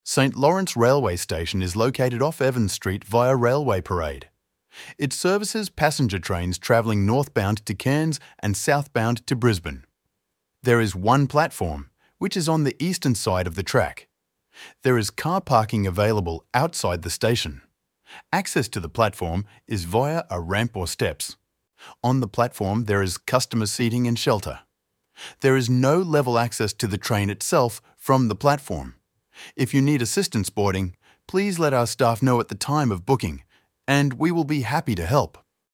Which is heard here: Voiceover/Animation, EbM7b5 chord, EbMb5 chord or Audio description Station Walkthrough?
Audio description Station Walkthrough